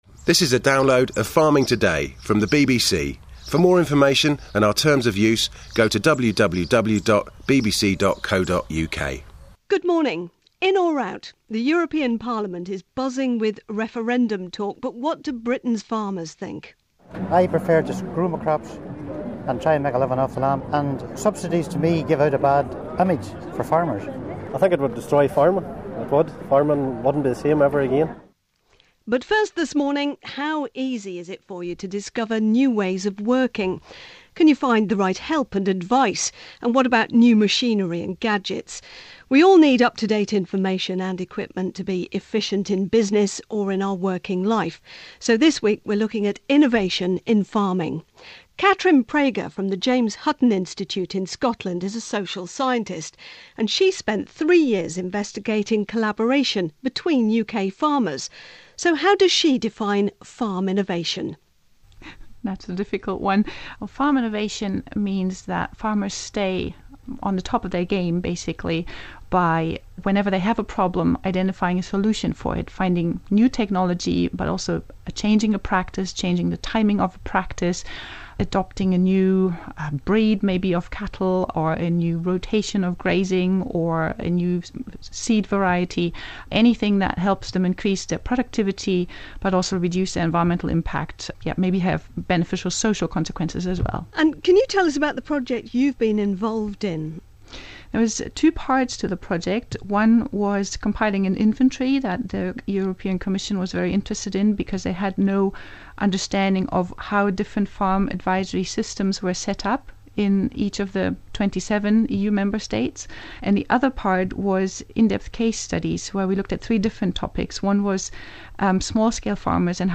PROAKIS on the radio